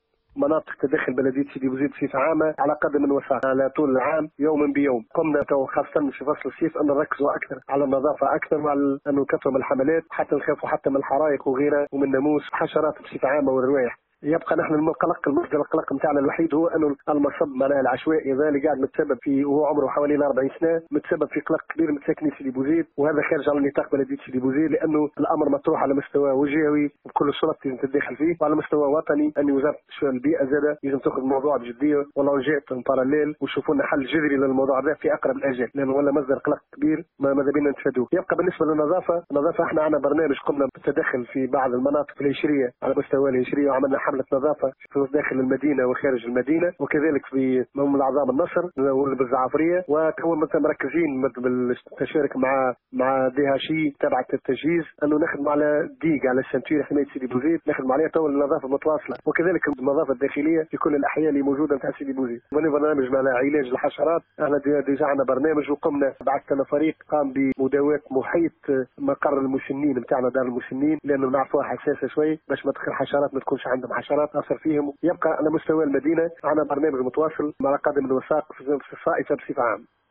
Le maire de Sidi Bouzid, Mansour Abdouli a assuré dans une déclaration accordée à Tunisie Numérique que les services municipaux ont commencé à organiser des campagnes de nettoyage périodiques qui couvraient toute la région ainsi que des campagnes de lutte contre les insectes dans différentes zones de la région en question.